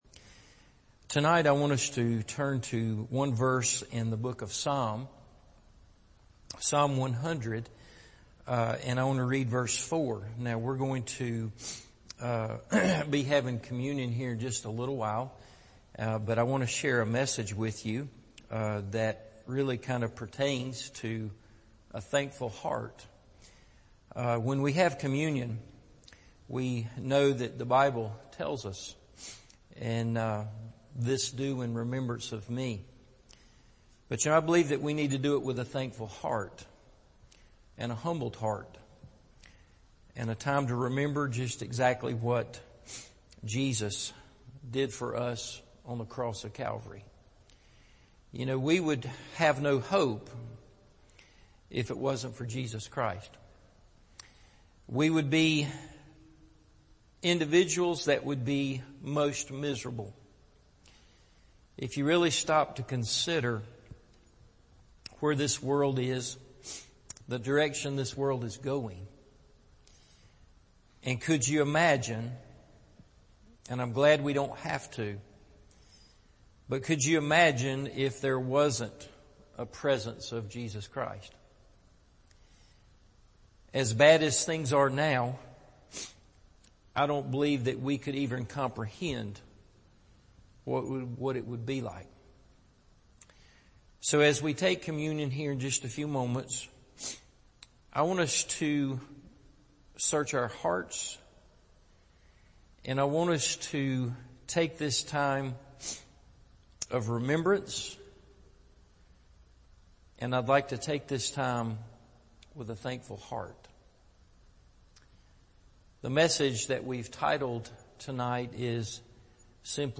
Jesus, I’m Thankful – Evening Service